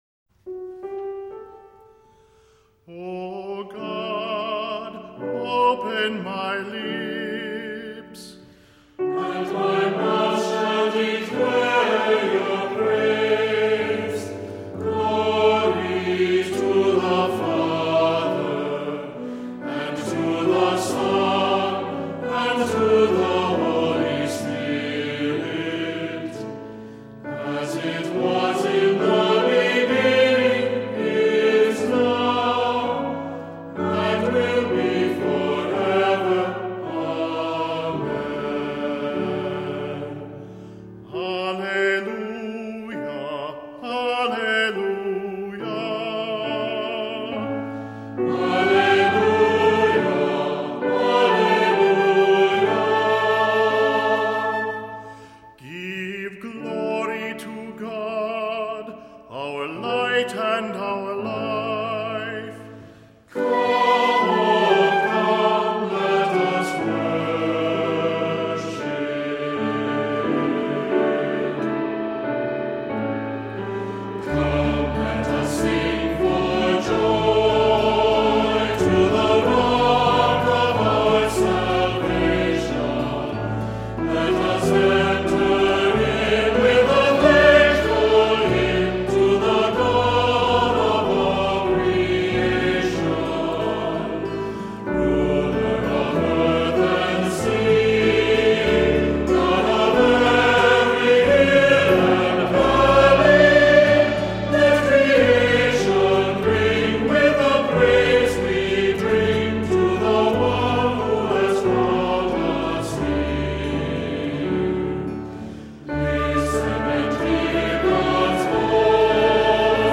Voicing: Cantor; Priest; Leader; Assembly